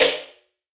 CLAP - SLAP.wav